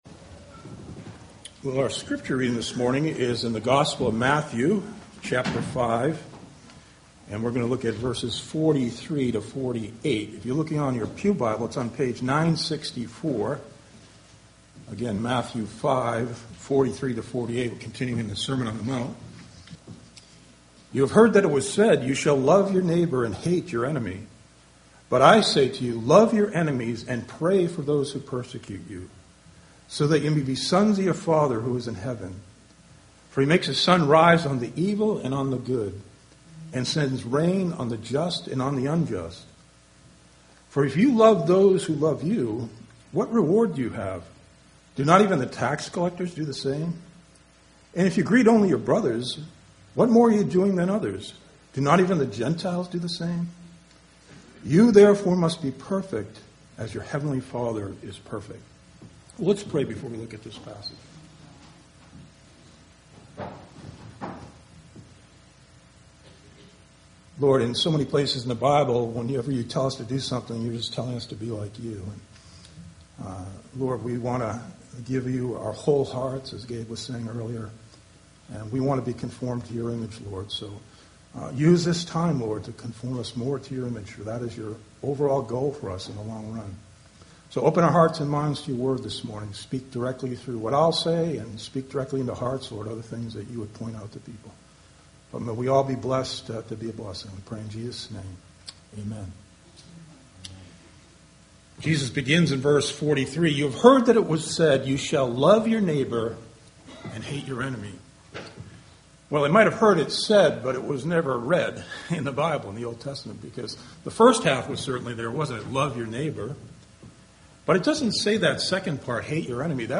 October 2019 Sermon on the Mount Matthew Scripture: Matthew 5:43-48 Download: Audio